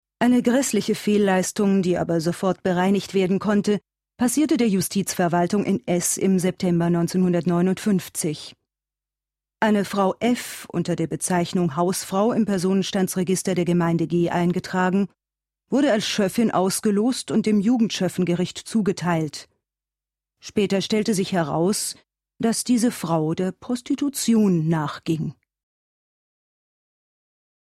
deutsche Sprecherin, Sängerin und Schauspielerin.
Sprechprobe: Sonstiges (Muttersprache):